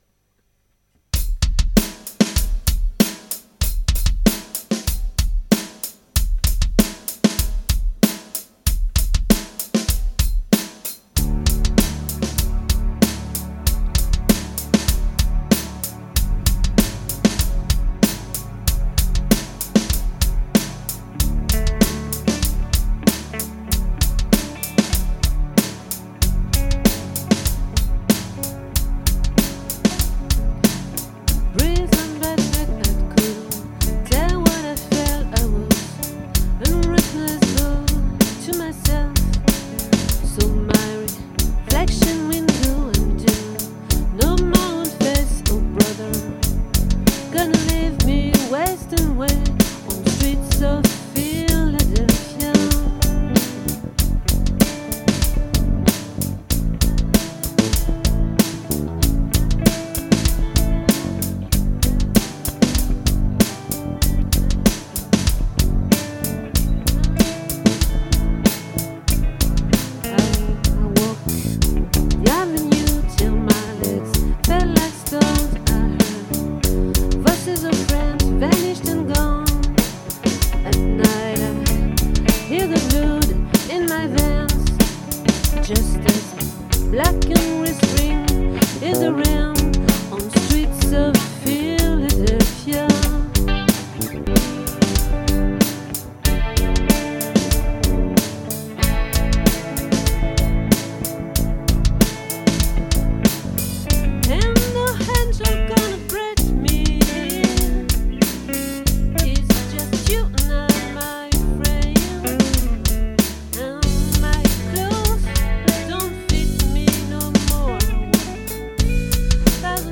🏠 Accueil Repetitions Records_2022_01_05